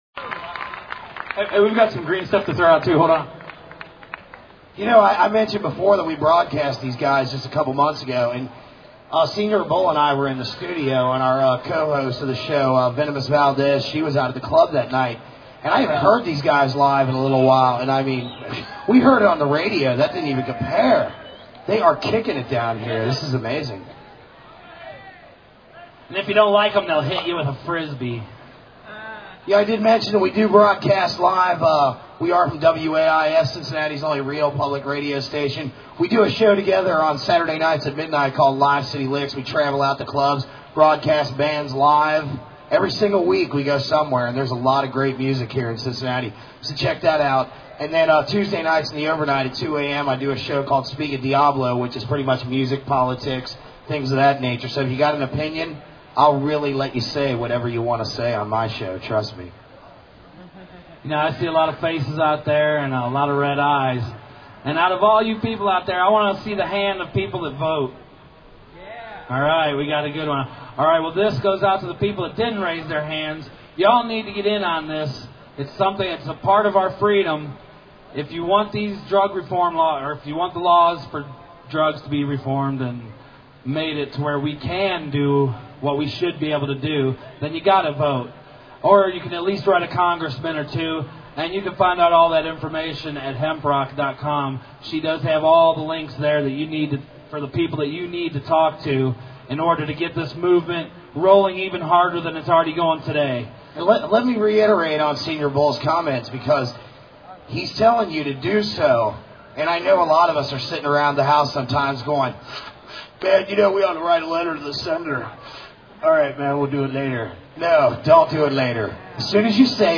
Cincinnati's 2003 MMM was a 3 hour Rally held at the
Downtown public gathering place, Fountain Square.